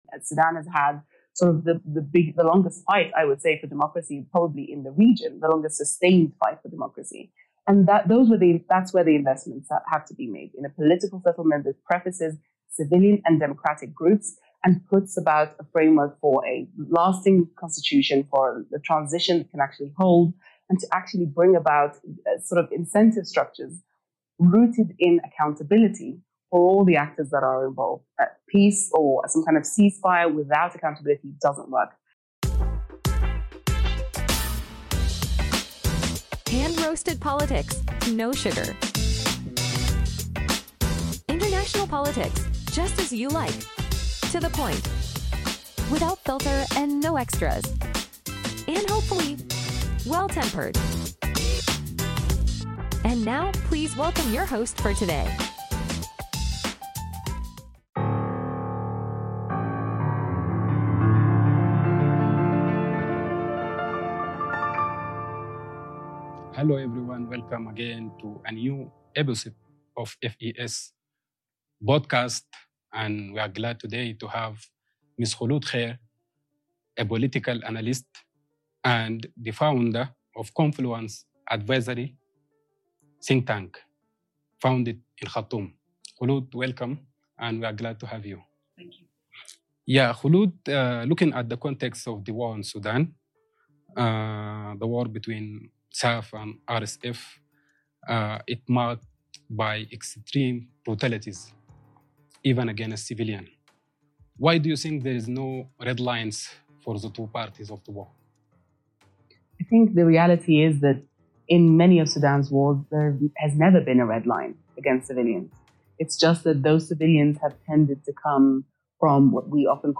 Recorded November 22nd, 2024, in Nairobi.
We apologise for the poor audio quality; unfortunately, the original file we received was corrupted.